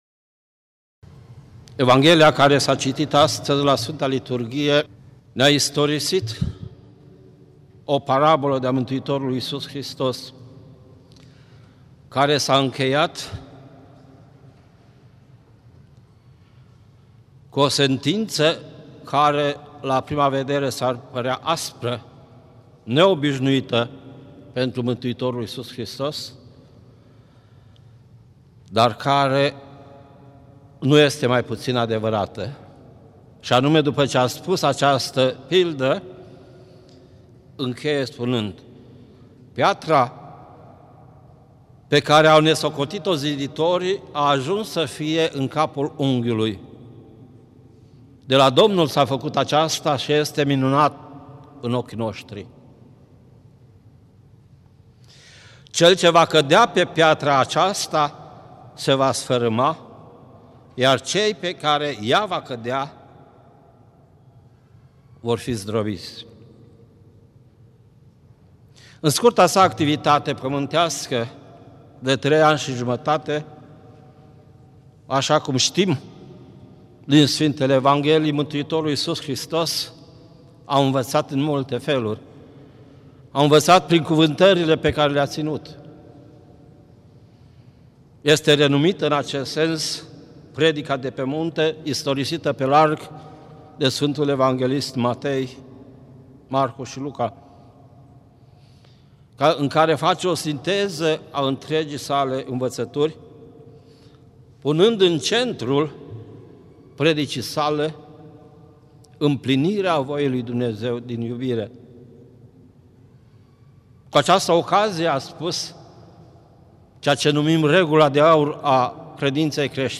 Predică la Duminica a 13-a după Rusalii
Cuvânt de învățătură
Pilda lucrătorilor răi), în cadrul slujbei Vecerniei